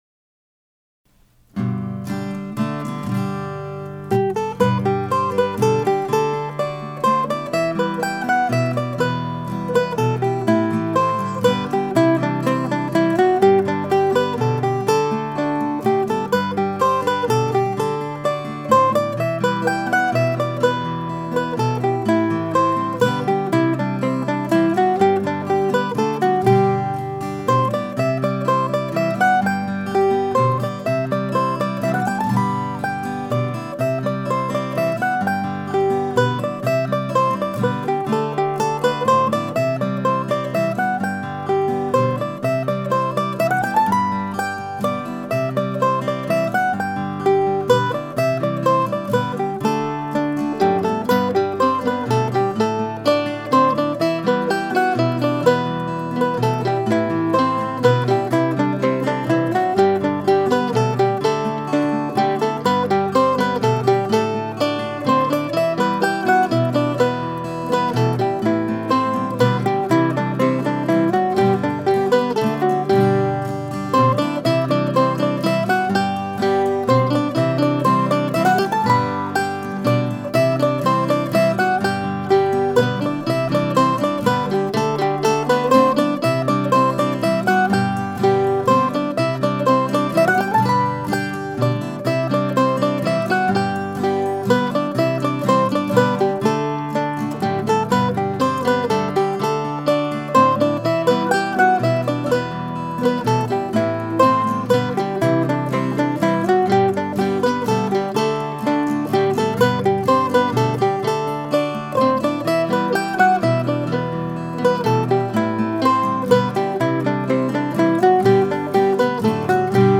I picked up the mandolin first thing this morning and a little snatch of melody was waiting.
Following my late breakfast I tuned up the guitar and plugged in a mic, then I recorded the music while it was still fresh.